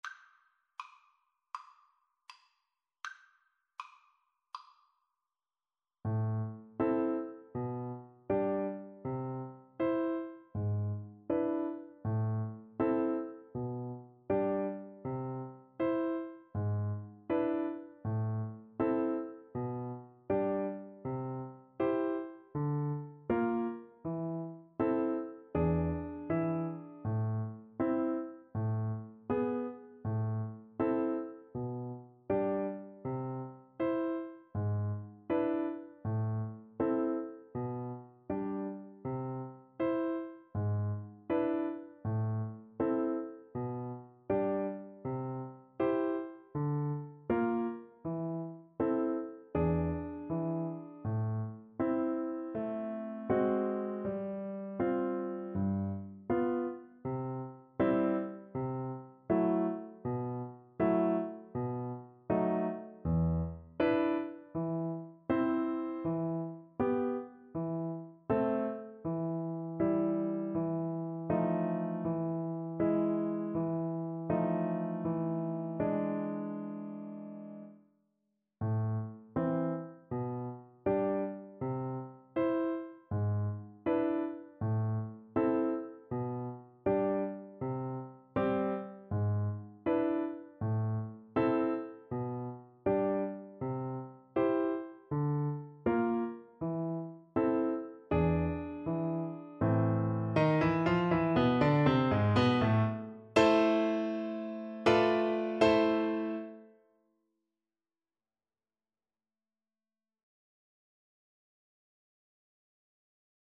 Play (or use space bar on your keyboard) Pause Music Playalong - Piano Accompaniment Playalong Band Accompaniment not yet available reset tempo print settings full screen
Andante = c.80
A minor (Sounding Pitch) (View more A minor Music for Viola )
Classical (View more Classical Viola Music)